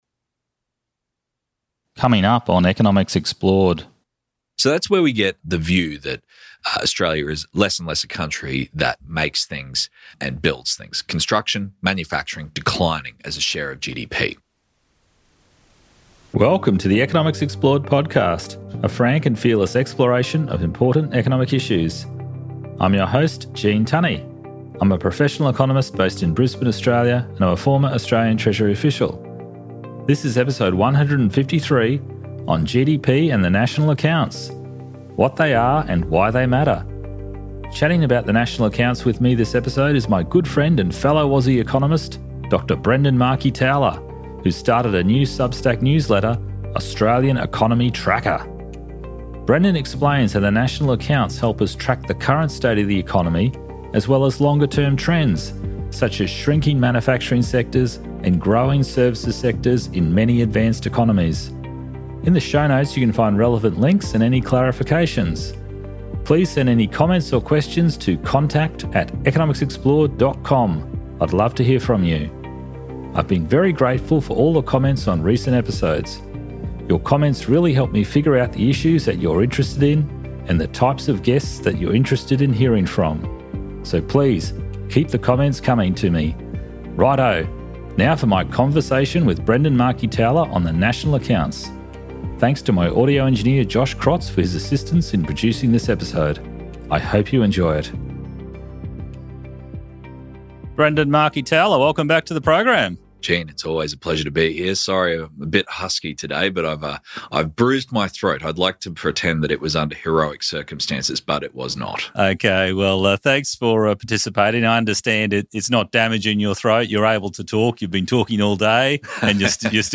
This episode features a conversation